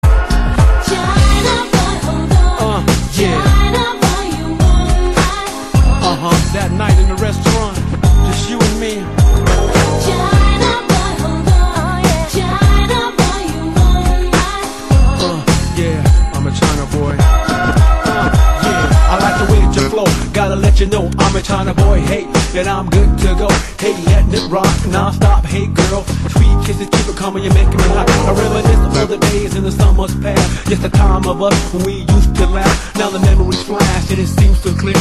DJ铃声 大小